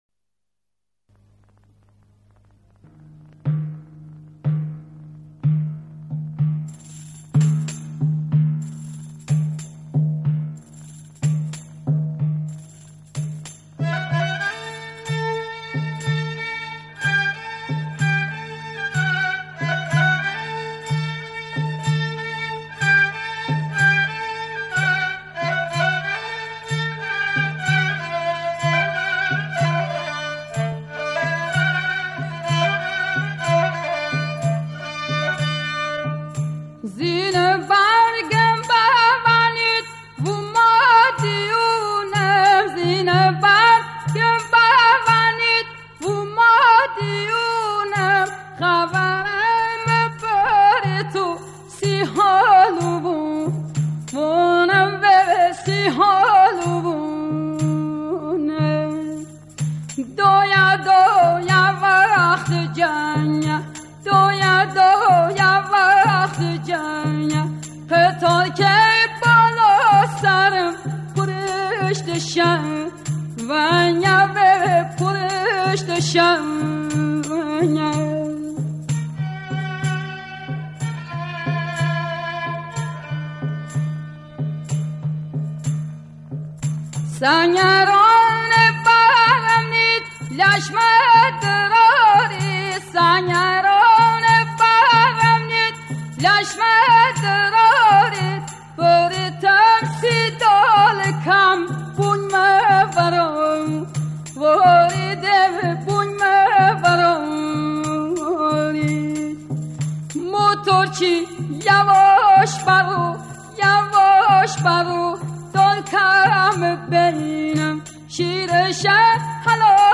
آهنگ لری